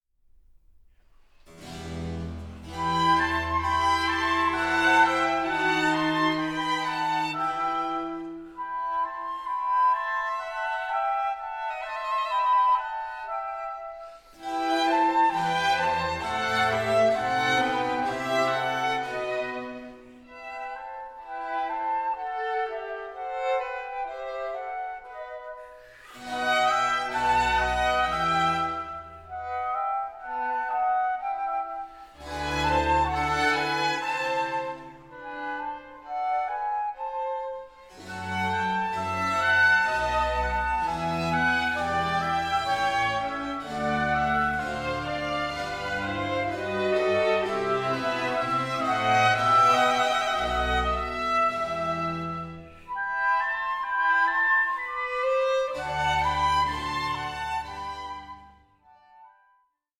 for Violin, Flute & Oboe